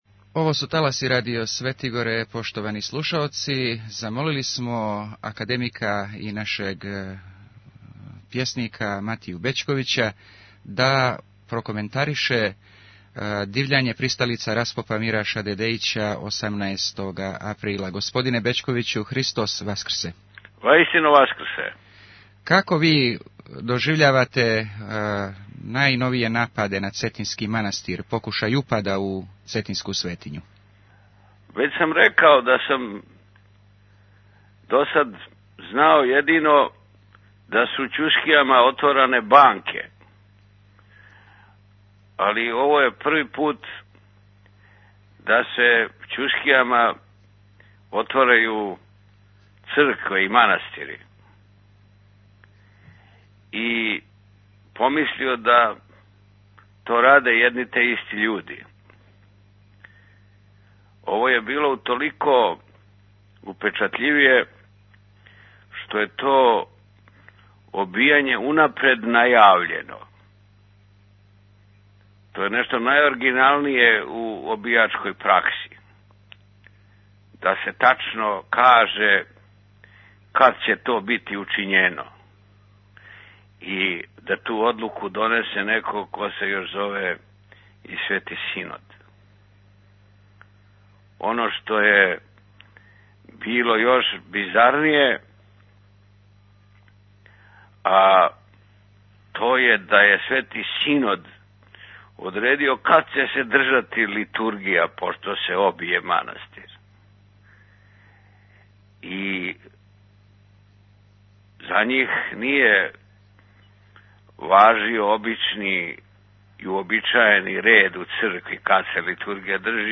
Нападом на Цетињски манастир тзв. ЦПЦ је оправдала свој углед у народу, казао је нашем радију академик Матија Бећковић.